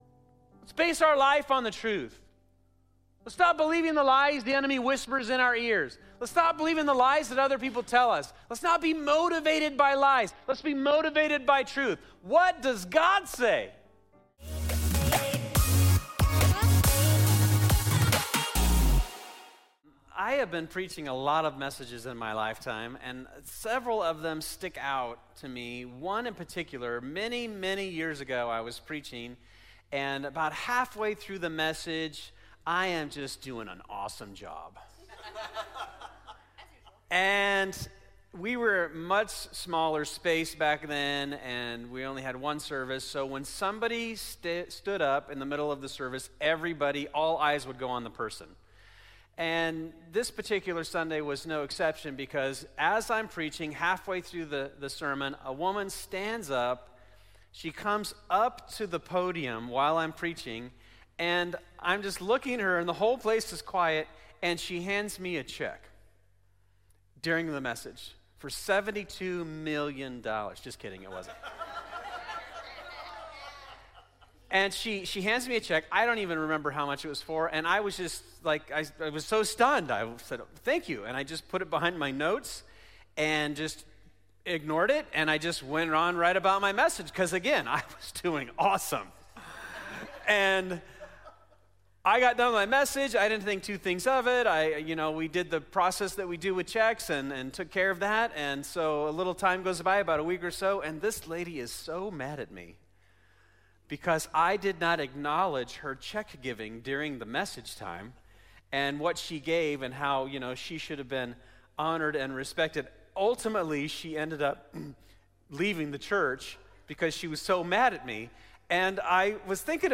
2025 The After Party Church Hypocrisy Lying Sunday Morning "The After Party" is our series at Fusion Christian Church on the book of Acts.